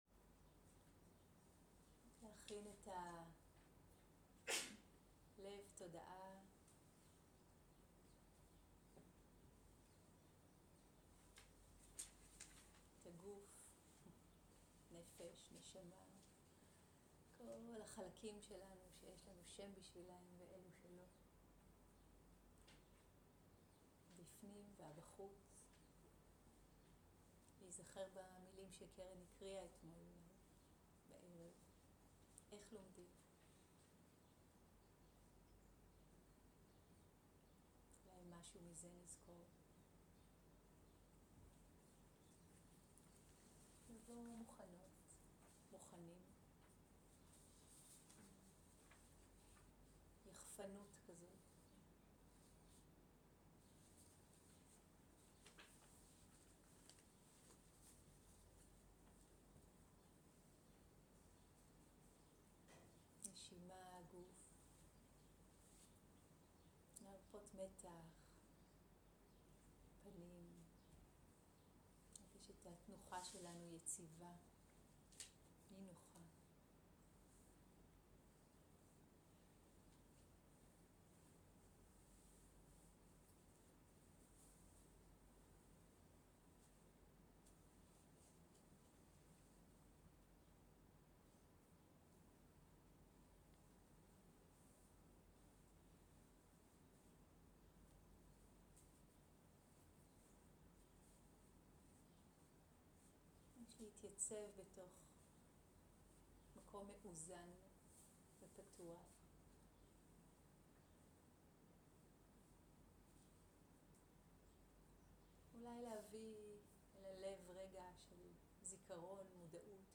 שיחות דהרמה שפת ההקלטה